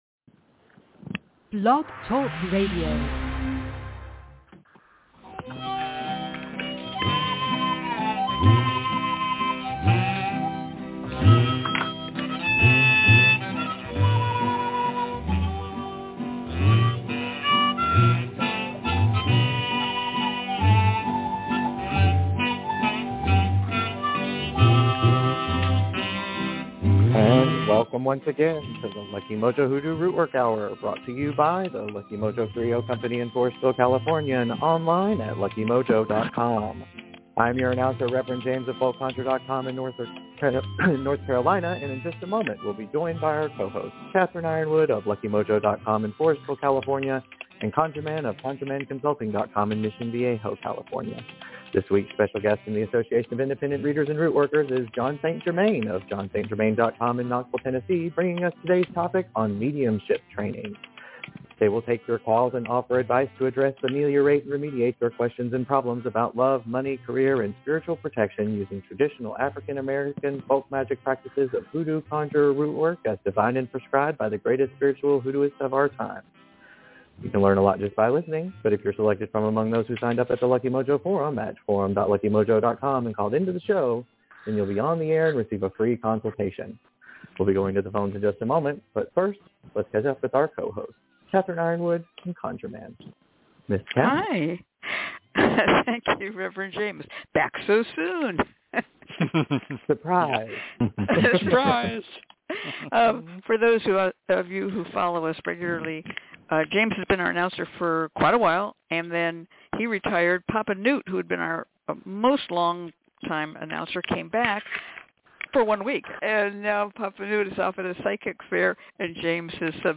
We begin this show with a Tutorial on Mediumship Training and provide 90 minutes of free readings, free spells, and conjure consultations, giving listeners an education in African-American folk magic. We'll also announce the winners of our weekly Facebook Fridays contest.